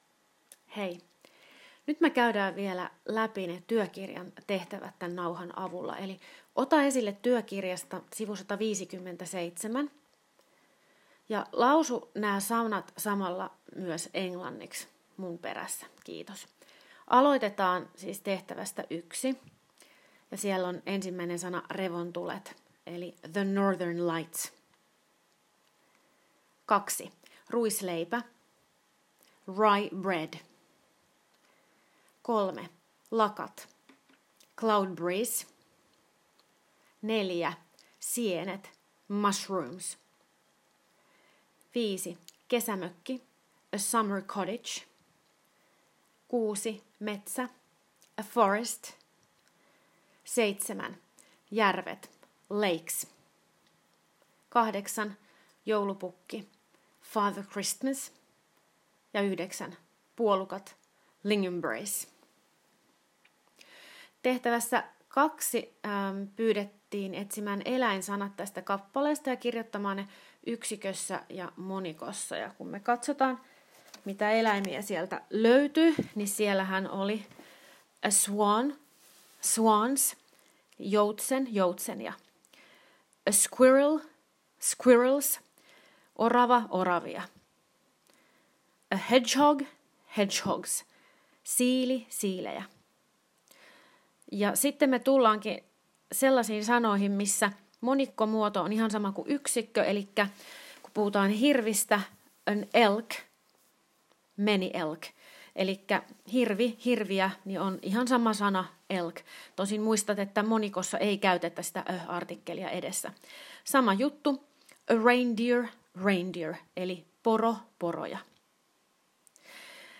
s. 157 ja 160 työkirjasta ääneen luettuina suomennoksineen